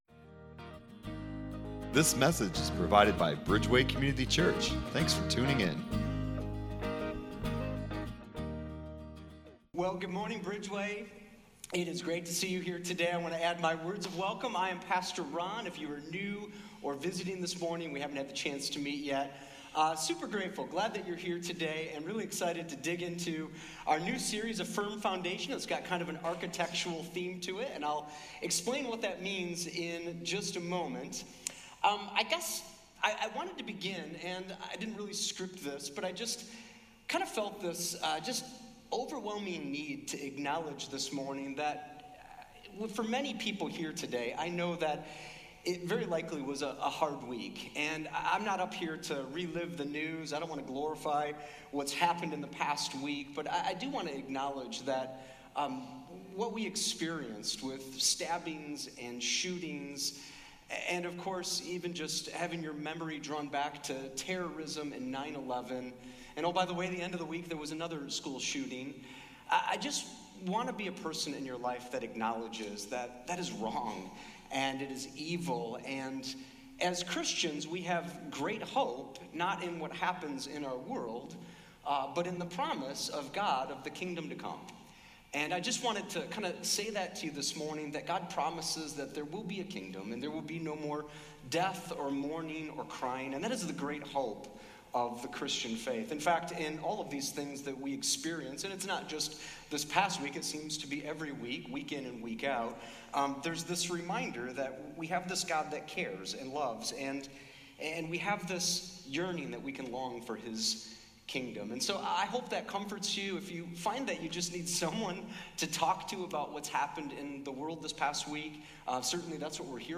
Download Sermon Discussion Guide